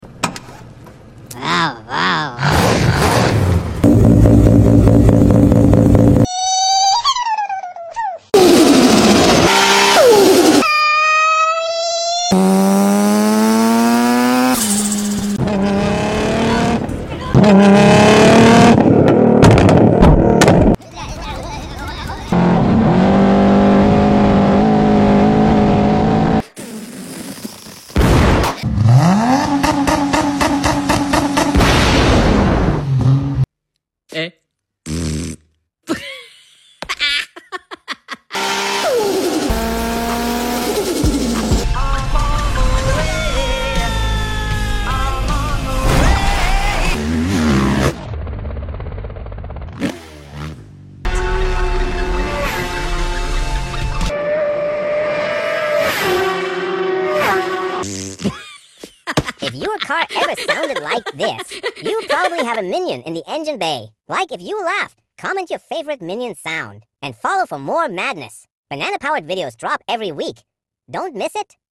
Minions Car Sound Compilation | sound effects free download